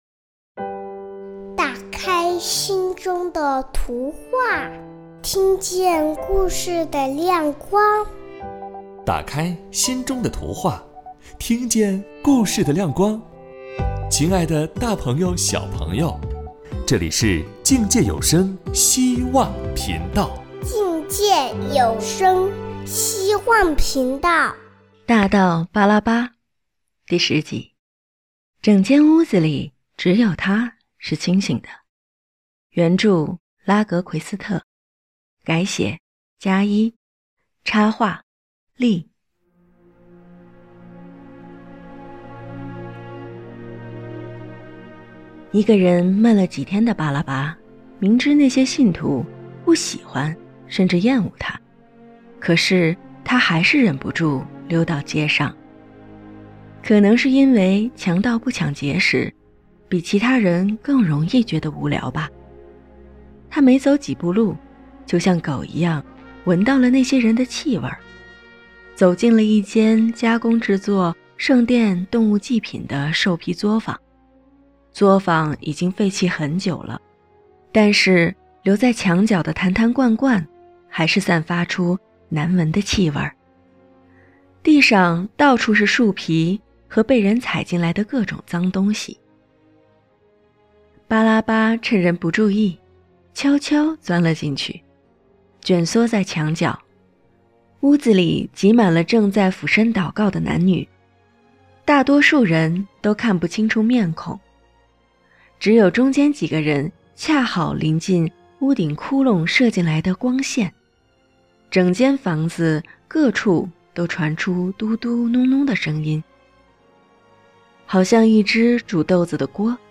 有声绘本｜“啃食节”上：夺冠的虫子与人的眼泪 – 境界在线